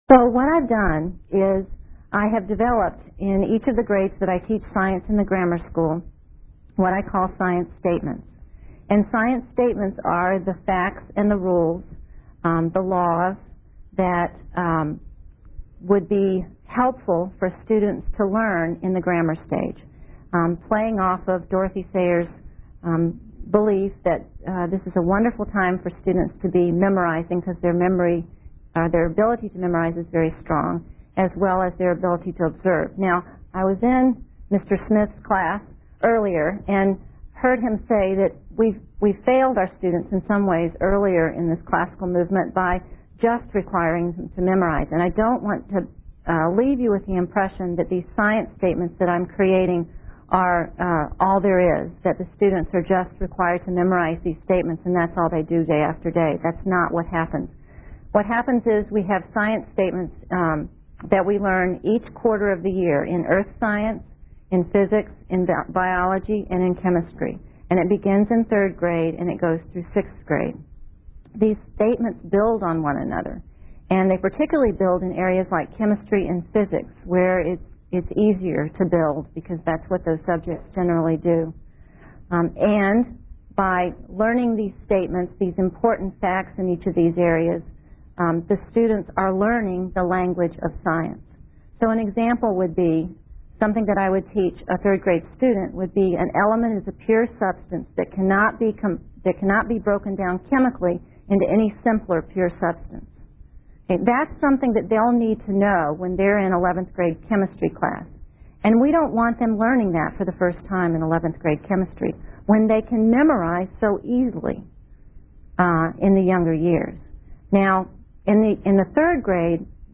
Panel Discussion and Q&A #2
2003 Workshop Talk | 1:03:23 | All Grade Levels